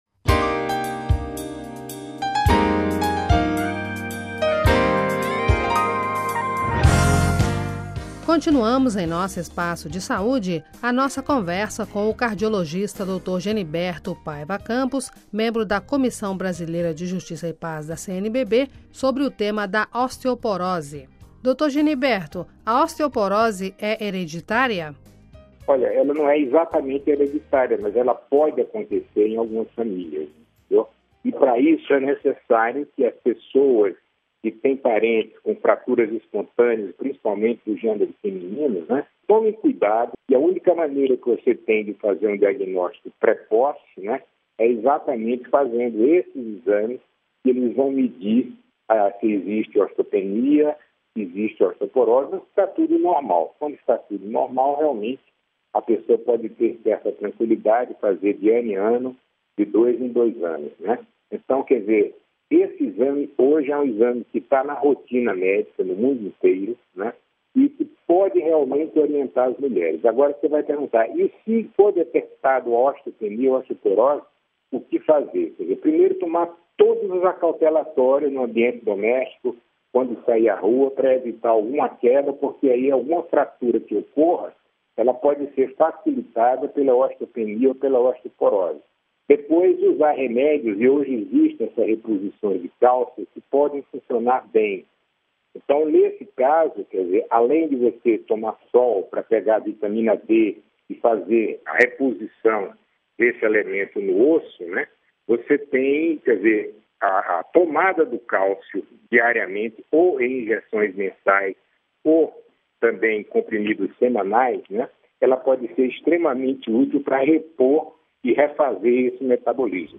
conversa